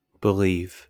wymowa:
bryt. (RP), amer.: IPA/bɪˈliːv/ lub /bəˈliːv/, X-SAMPA: /bI"li:v/ lub /b@"li:v/